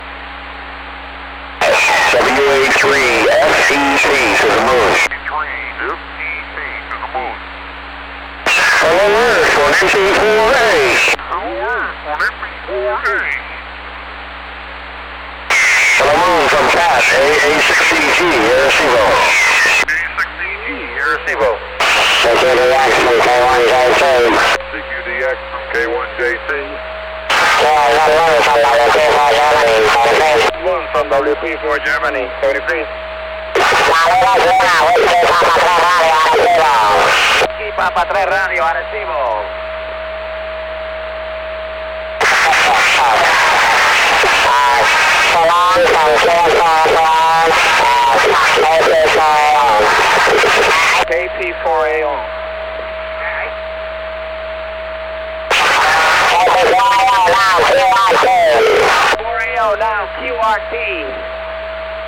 They ran 400 watts and used the observatory's 1000 foot radio telescope. They made worldwide contacts on CW, SSB, and JT65B.